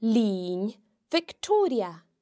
The slender nn can be heard in linn (a century):